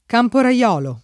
camporaiolo [ kampora L0 lo ]